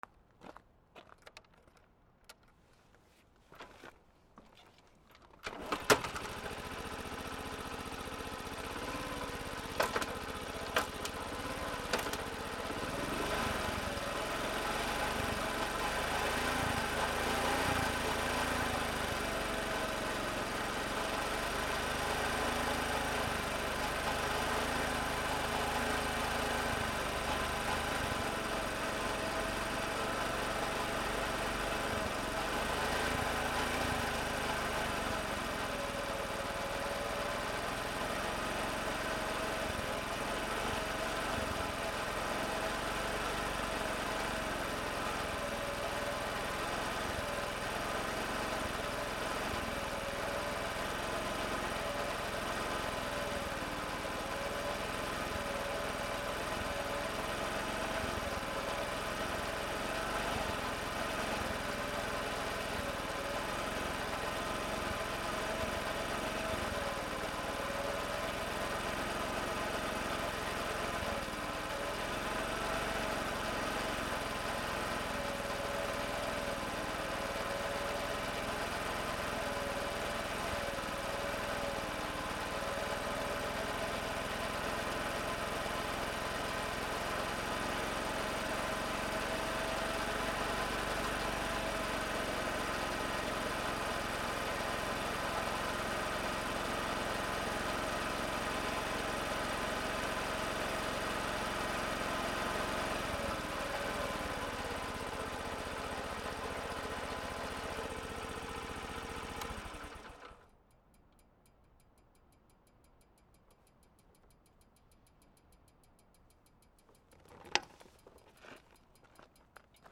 73走行音ループ
/ E｜乗り物 / E-30 ｜バイク / カブ録音